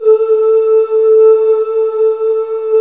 Index of /90_sSampleCDs/Club-50 - Foundations Roland/VOX_xMaleOoz&Ahz/VOX_xMale Ooz 1S